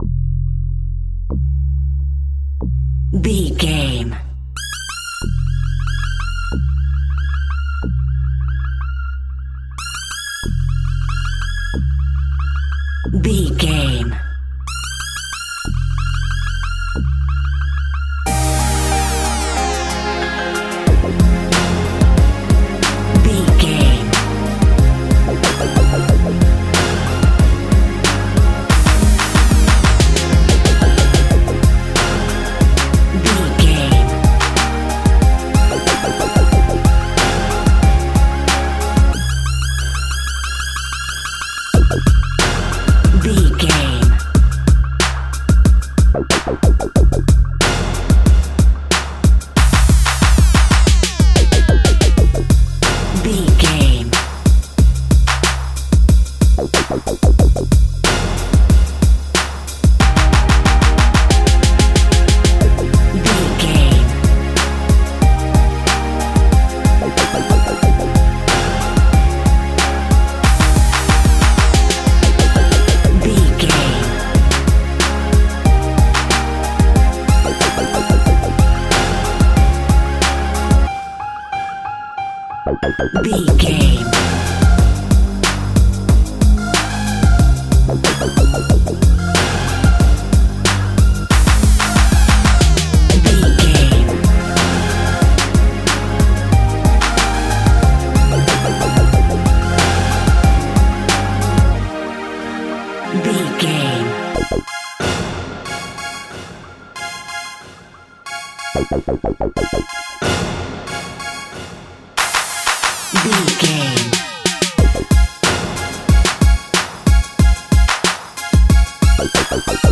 Aeolian/Minor
synthesiser
hip hop
soul
Funk
acid jazz
confident
energetic
bouncy
funky